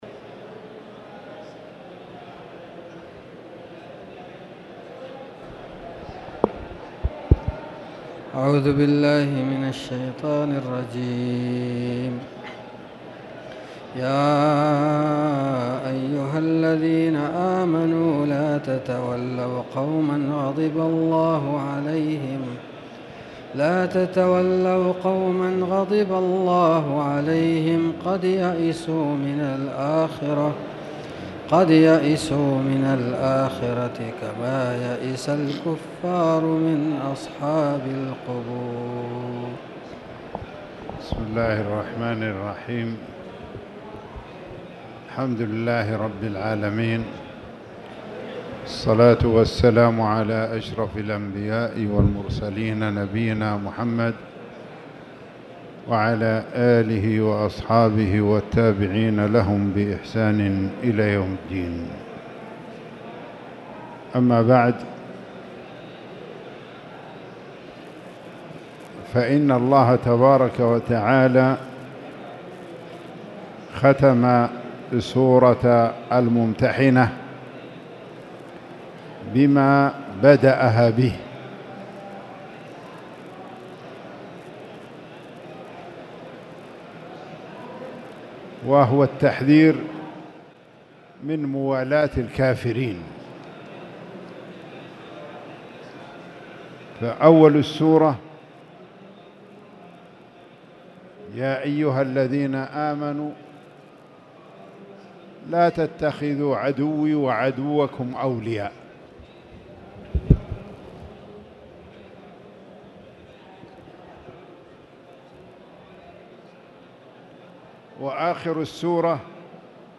تاريخ النشر ٢٦ ربيع الأول ١٤٣٨ هـ المكان: المسجد الحرام الشيخ